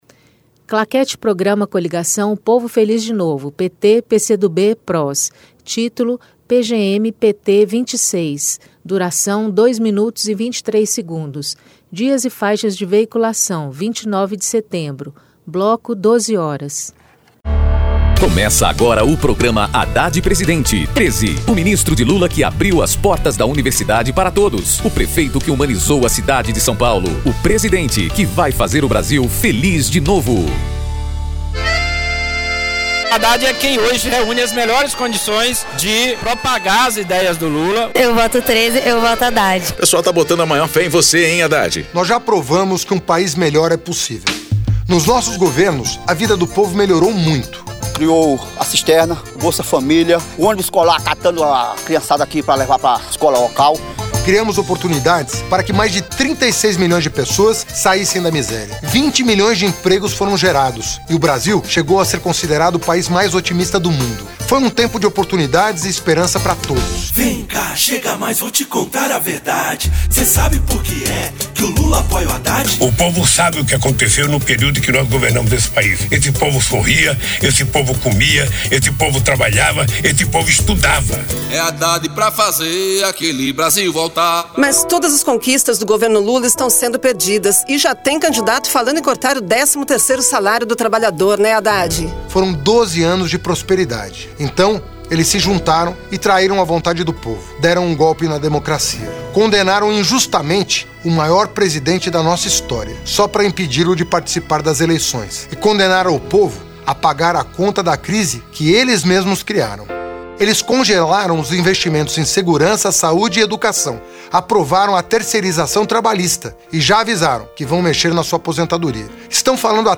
Descrição Programa de rádio da campanha de 2018 (edição 26) - 1° turno